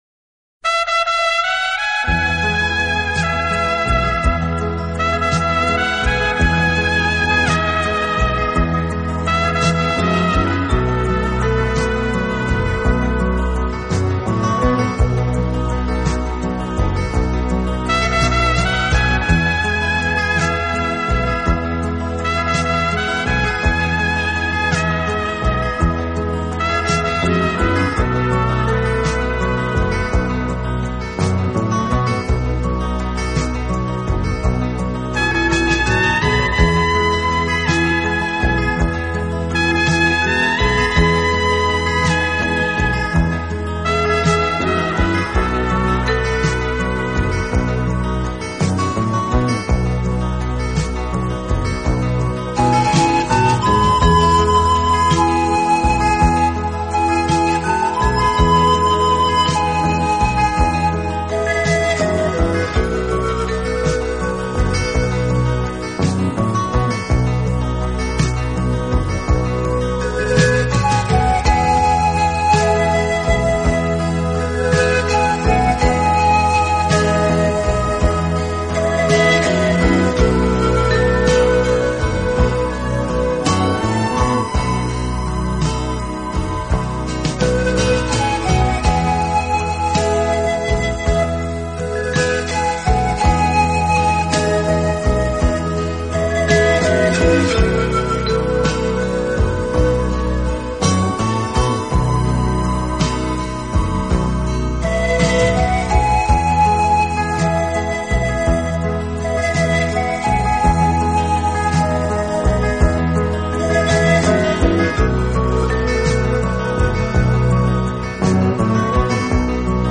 出的优美旋律悠长而略带惆怅，像夕阳下眺望着远处绵绵起伏的山峦，带给人惆思和遐想。